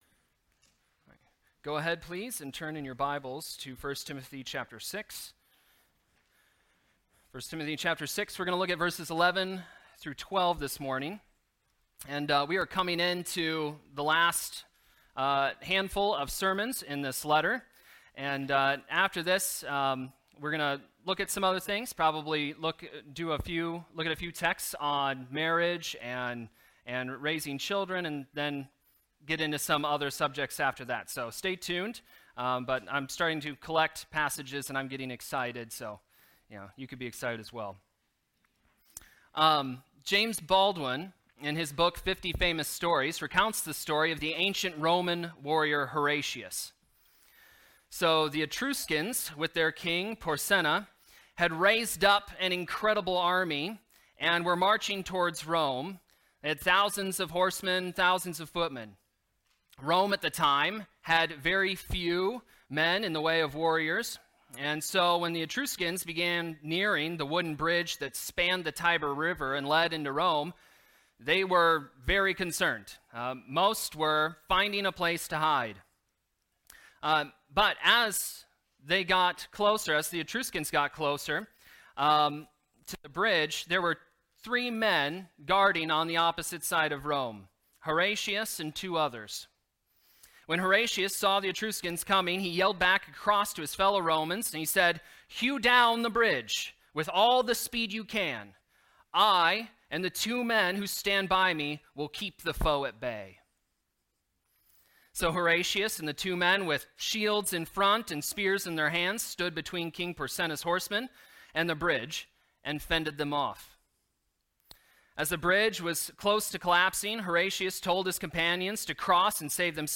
Timothy Sermons